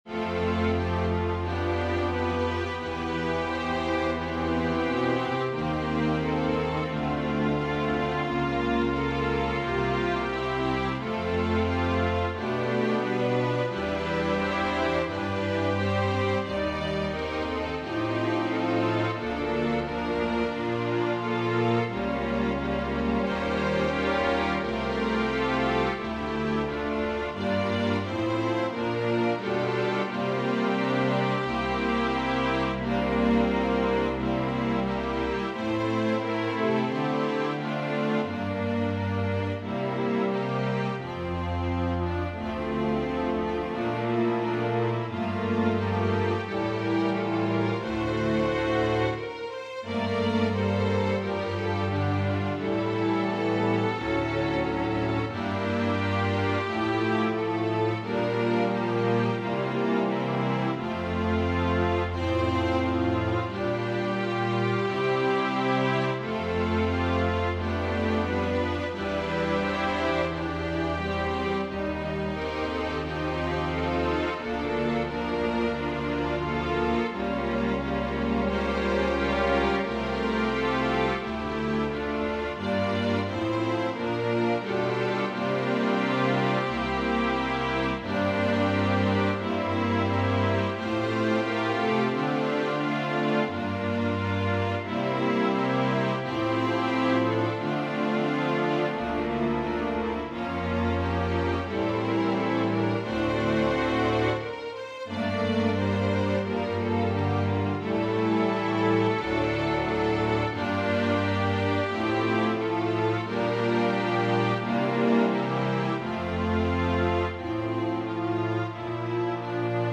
Voicing/Instrumentation: Organ/Organ Accompaniment We also have other 23 arrangements of " Families Can Be Together Forever ".